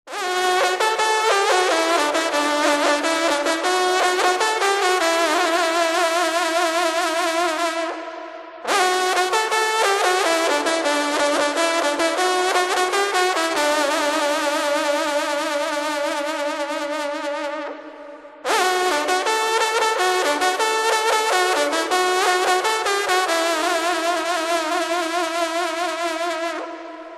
Catégorie Musique